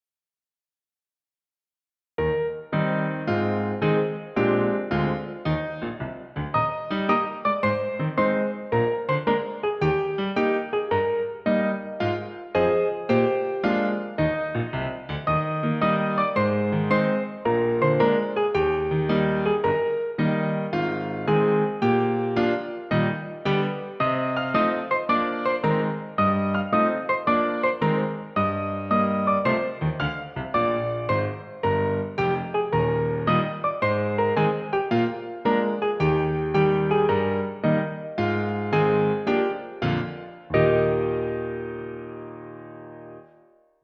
Piano accompaniment
Musical Period 19th century British, Australian, American
Tempo 110
Rhythm March
Meter 4/4